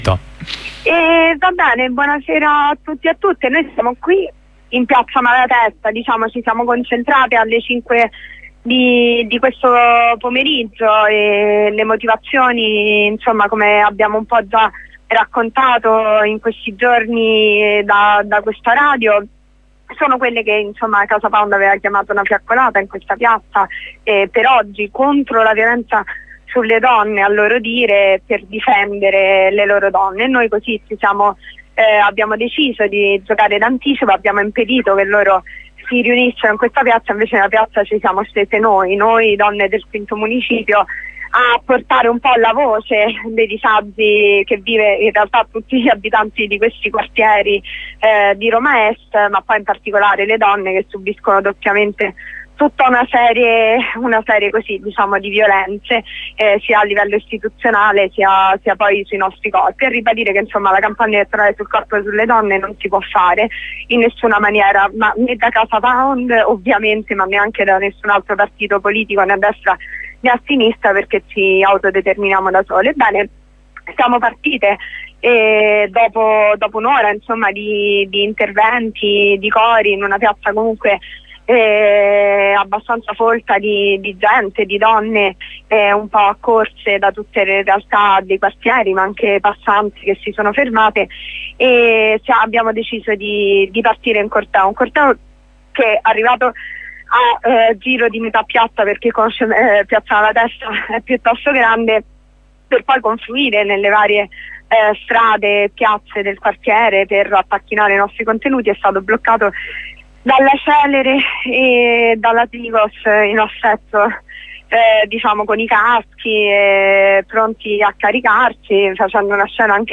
Insieme ad una compagna dalla piazza proviamo a tracciare un bilancio della giornata e ricordiamo le prossime iniziative.